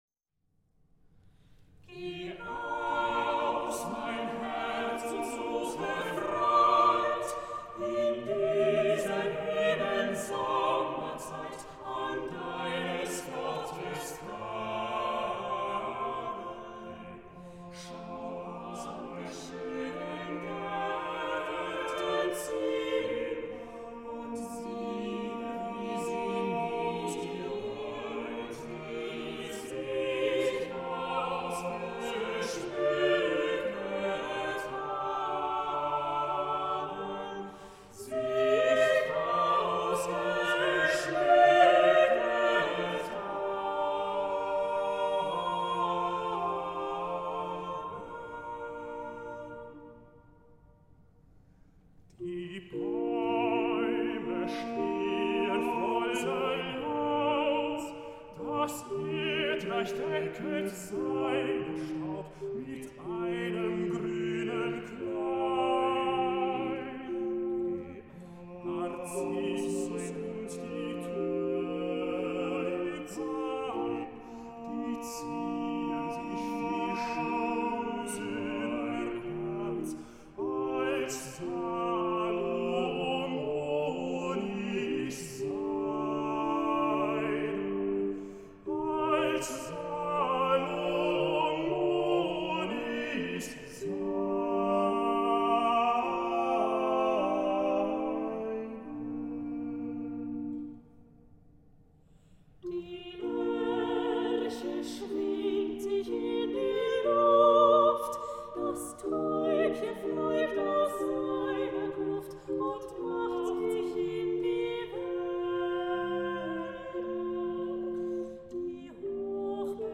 Max Bruchs Werk wahrt die traditionelle Form der Romantik, wie sie auch Felix Mendelssohn Bartholdy und Johannes Brahms gepflegt haben.